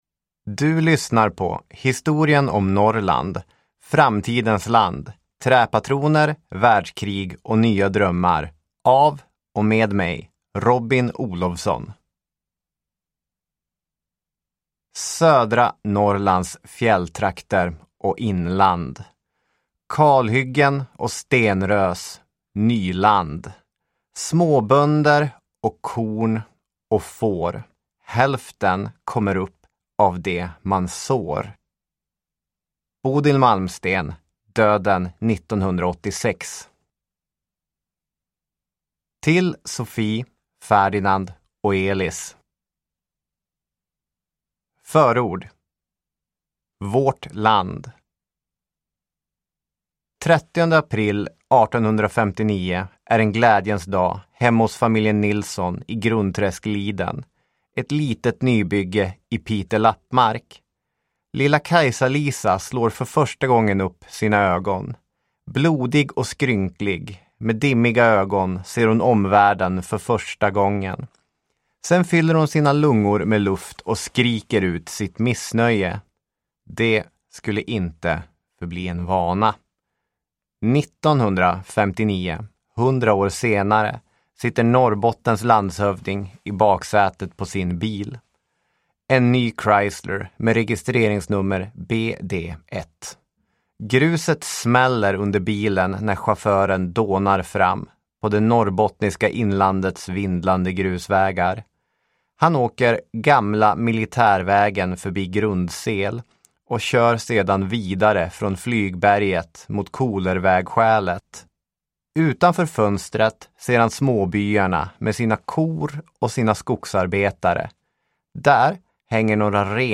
Historien om Norrland. Del 2, Framtidens land – Ljudbok
• Ljudbok